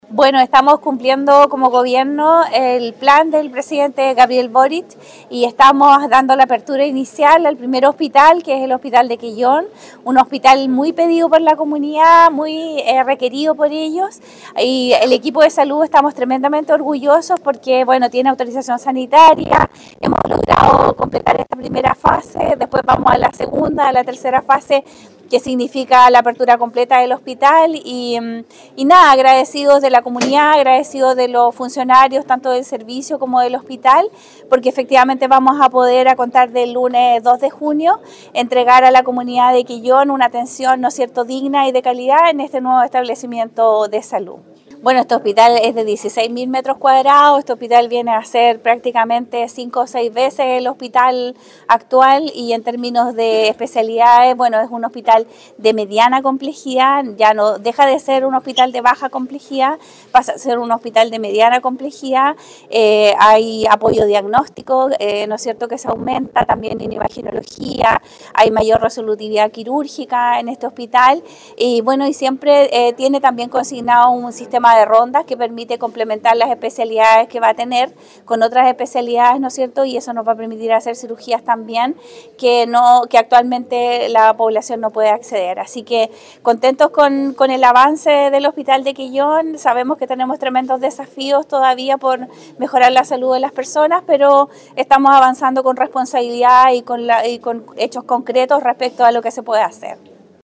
Por su parte, la directora del Servicio de Salud Chiloé, Marcela Cárcamo, agradeció el trabajo y la dedicación de los equipos de salud para concretar la puesta en marcha parcializada del nuevo recinto hospitalario: